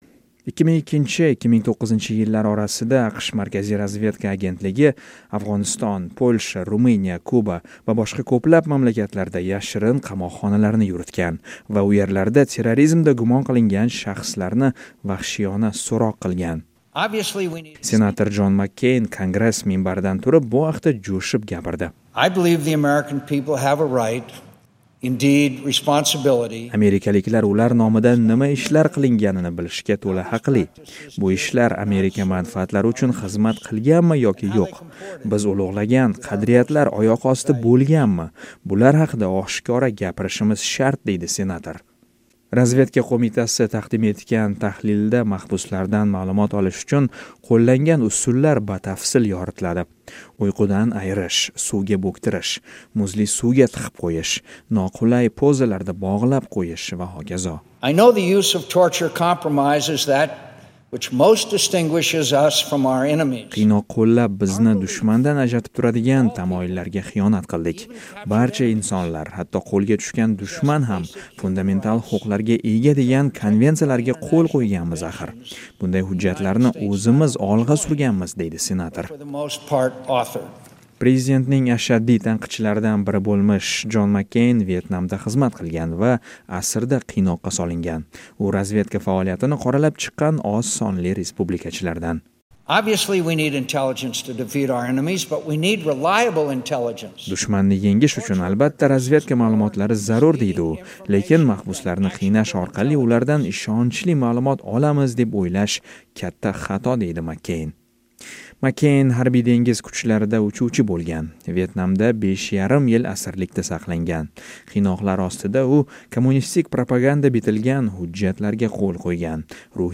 Senator Jon Makkeyn Kongress minbaridan turib bu haqda jo’shib gapirdi.